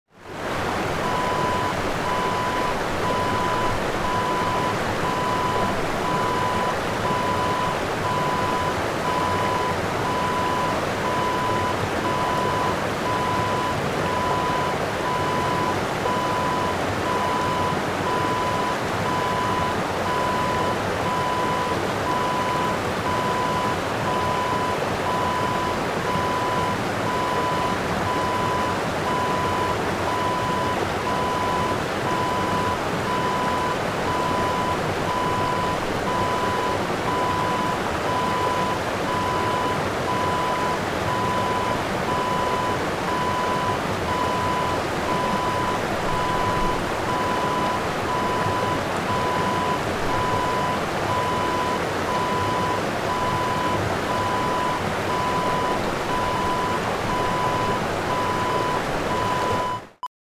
Waterfall Wav Sound Effect #1
Description: The sound of a waterfall in distance
Properties: 48.000 kHz 16-bit Stereo
A beep sound is embedded in the audio preview file but it is not present in the high resolution downloadable wav file.
Keywords: waterfall, water, fall, falls, river, rushing, stream, nature, cascade
waterfall-preview-1.mp3